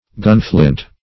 Gunflint \Gun"flint`\, n.